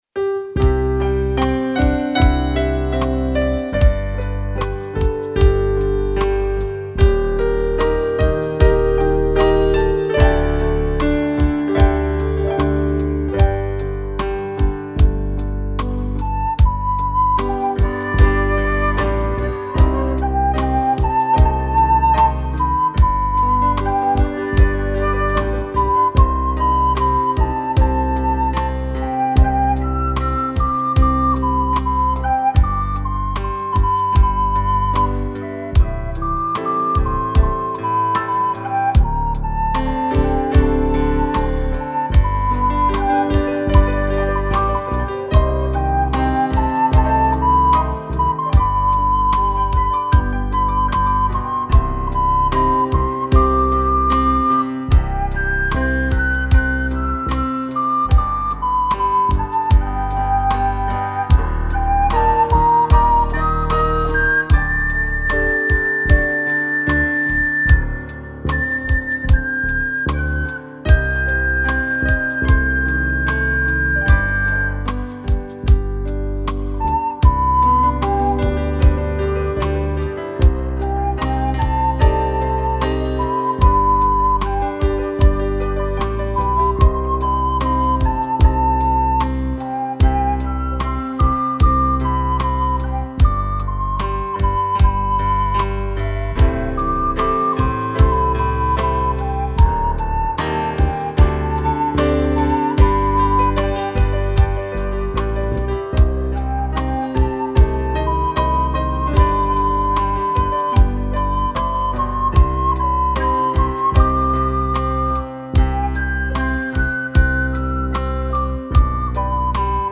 Sol Mayor